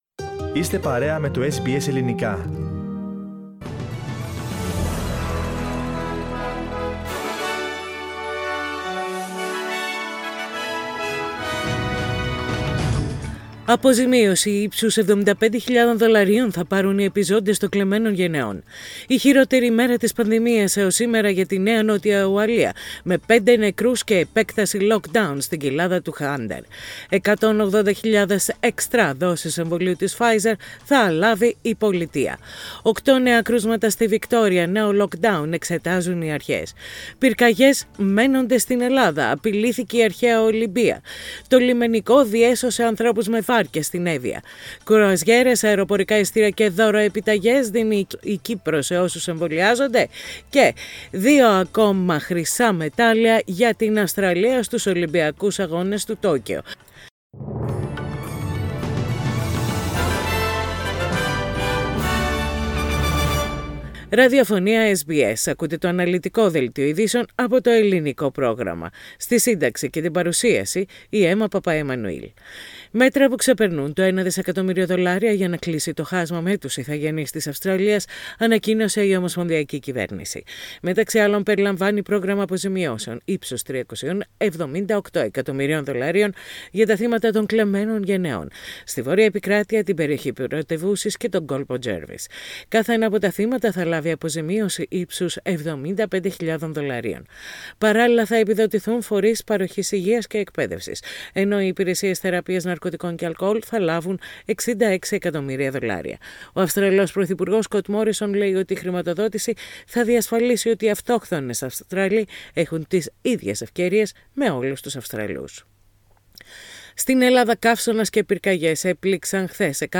Press Play on the main photo and listen the News Bulletin (in Greek) Source: SBS Greek